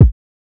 TS Kick_4.wav